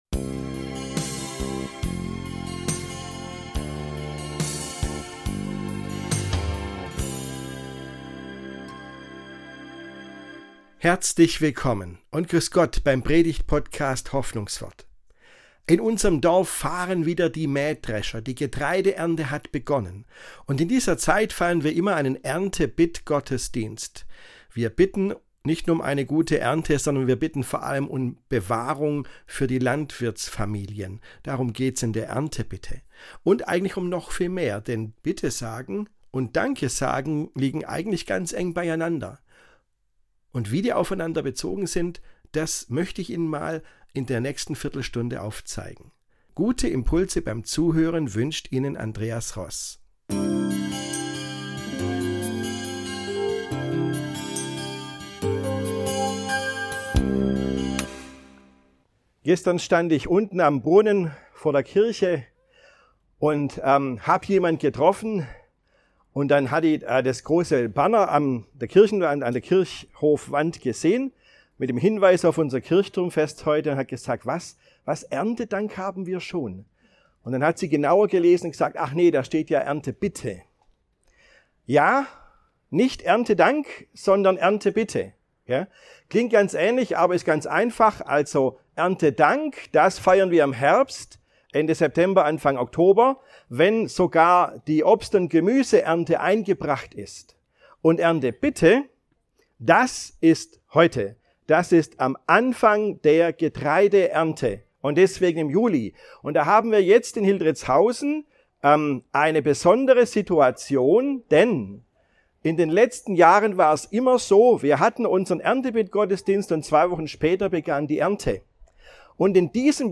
Da feiern wir einen Erntebittgottesdienst im Freien. Und obwohl es um „Bitten“ geht, soll diesmal ein Schlaglicht auf das Dankesagen fallen.